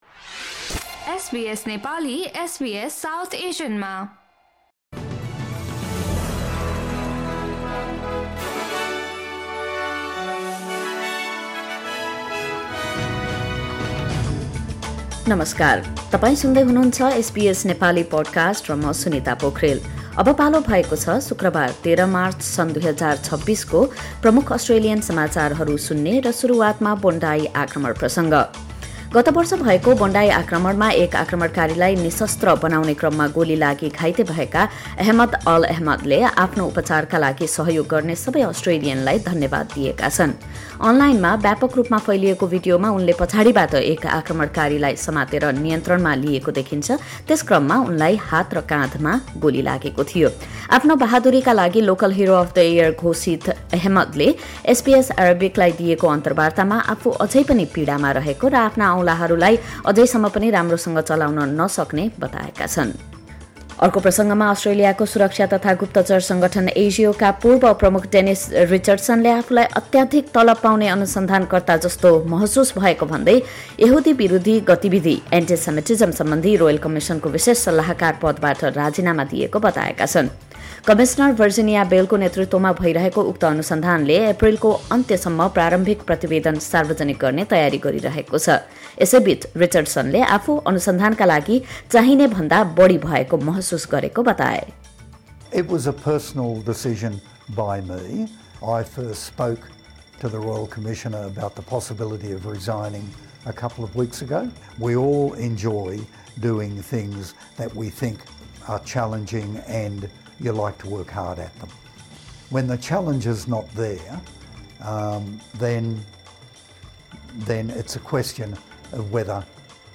SBS Nepali Australian News Headlines: Friday, 13 March 2026